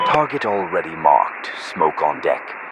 Radio-jtacSmokeAlreadyOut1.ogg